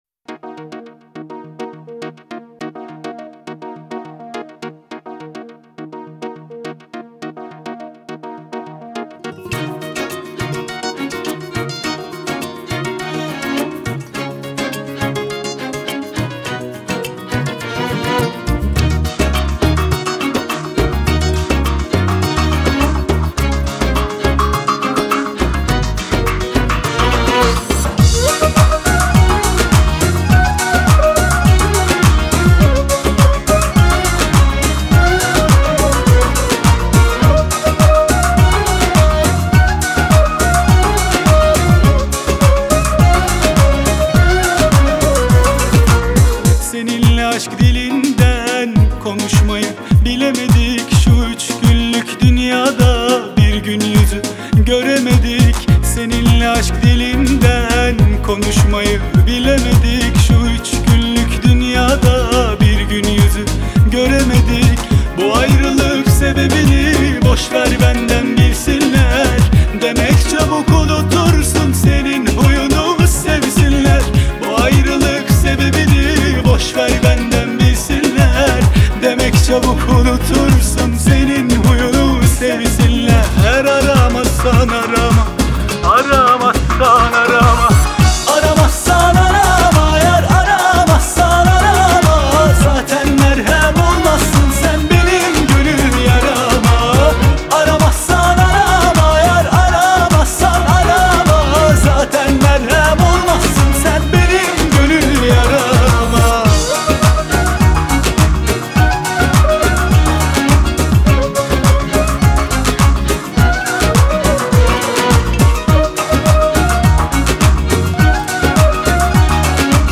آهنگ ترکیه ای آهنگ شاد ترکیه ای آهنگ نوستالژی ترکیه ای